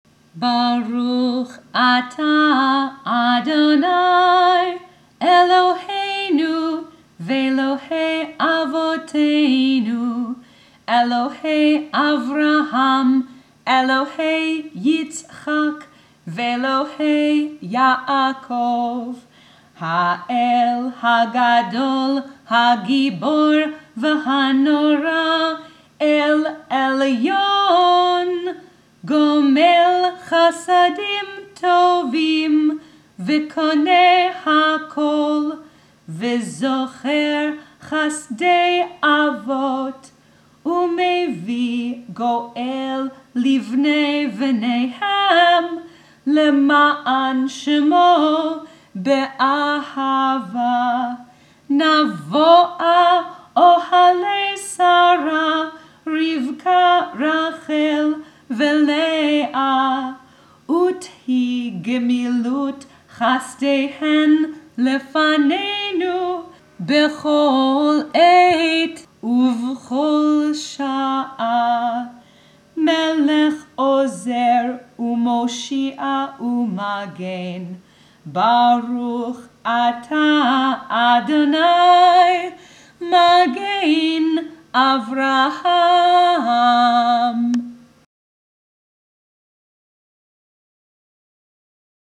Friday Night & Shabbat Morning Prayers